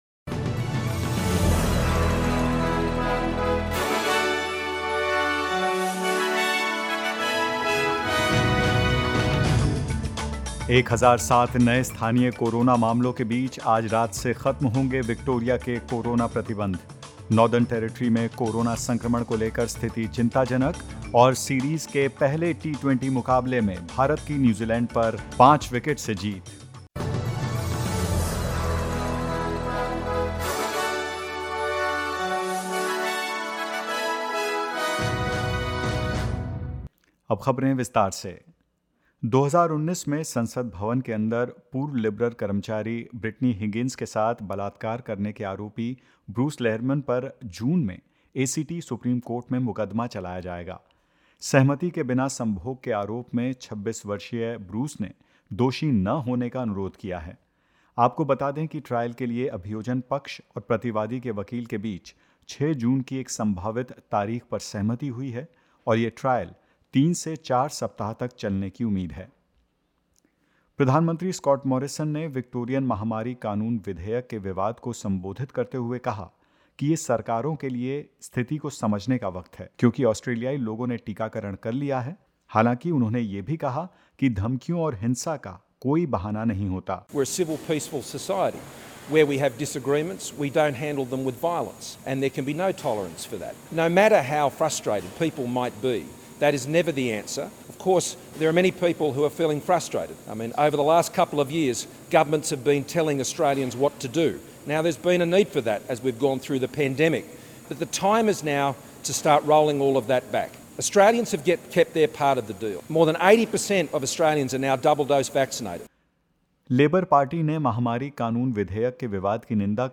In this latest SBS Hindi news bulletin of Australia and India: Debate on Victoria's proposed pandemic laws delayed, as police investigate threats against politicians; Northern Territory on high COVID-19 alert and more.